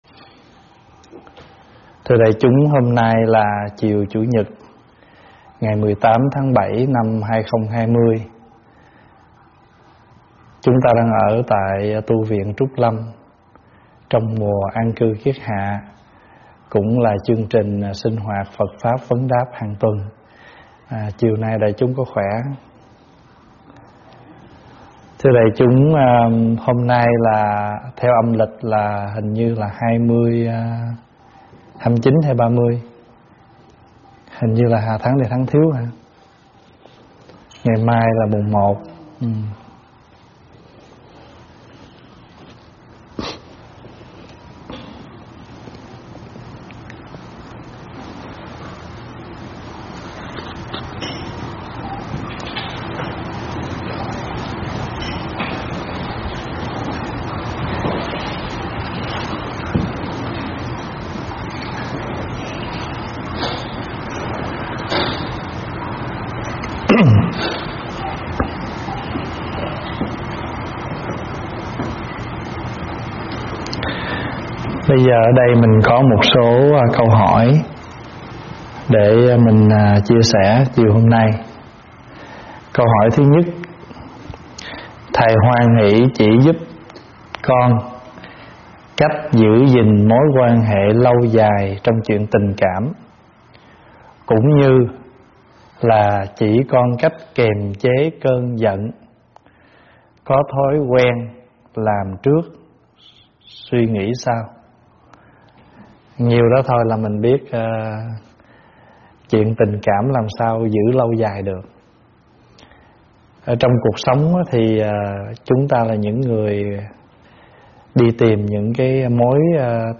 giảng tại tv Trúc Lâm